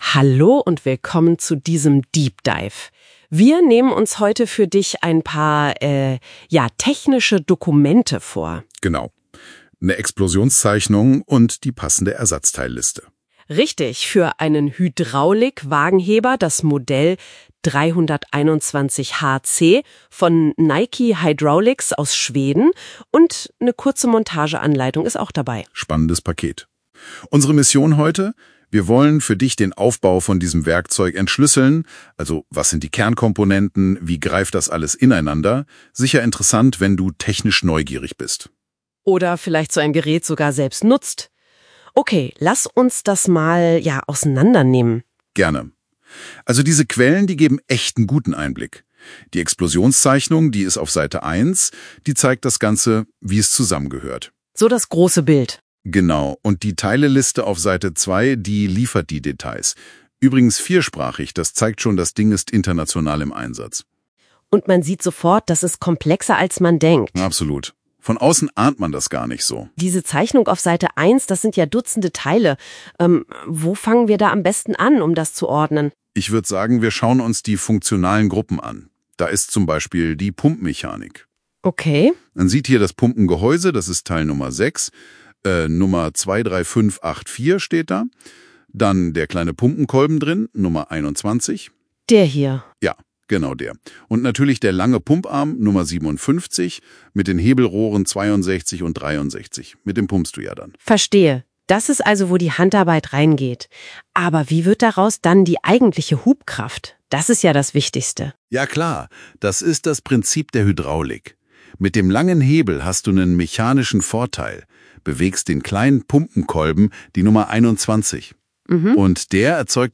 Wo man gleichzeitig aber Gemini ein dreiseitiges PDF über Ersatzteile geben kann und die (/der/das/ens?) macht daraus einen 6 min Podcast der gar nicht mal so schlecht ist. Zwei angenehme Sprecher und inhaltlich auch völlig okay, wenn auch nicht perfekt.
Anhänge ersatzteilliste_321hc (1).pdf ersatzteilliste_321hc (1).pdf 1,3 MB · Aufrufe: 78 gemini_generated_audio_overview_7ceba54b.mp3 gemini_generated_audio_overview_7ceba54b.mp3 3 MB